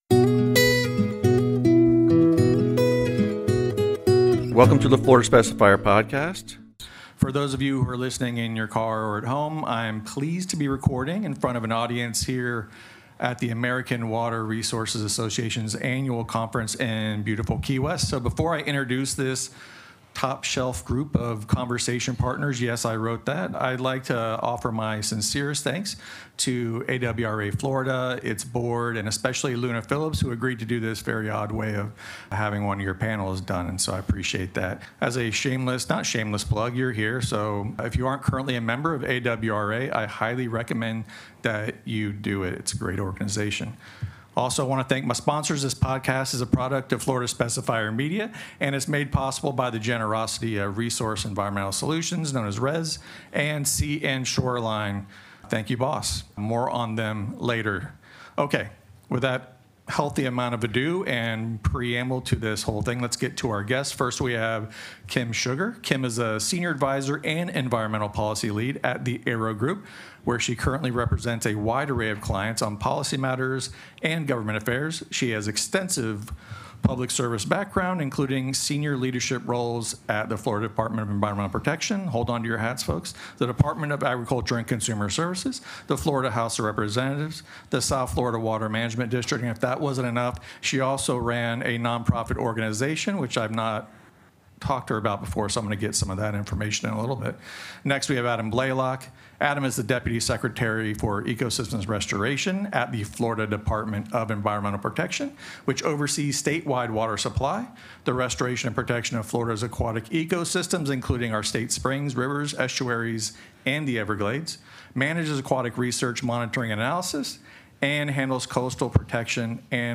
This special joint episode from Water for Fighting and the Florida Specifier Podcast—a product of the Specifier Media Group—was recorded in front of a live audience at the American Water Resources Association’s annual conference in Key West.